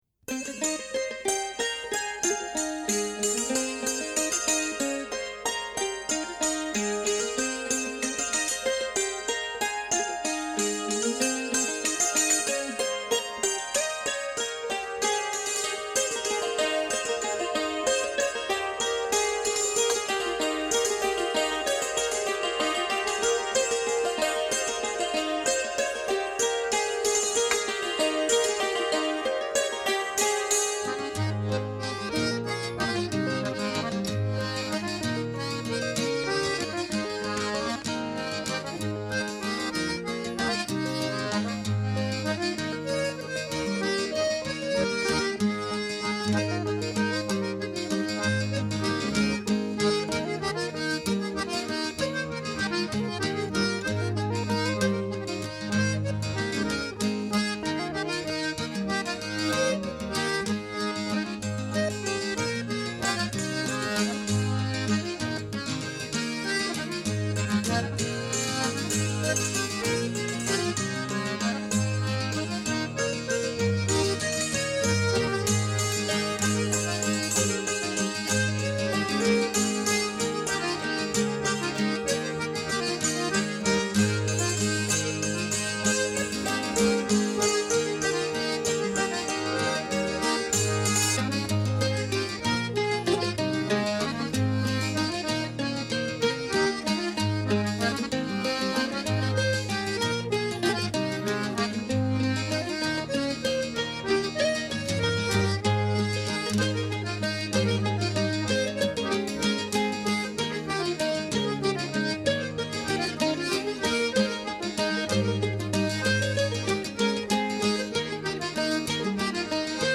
An East Anglian Ceilidh Band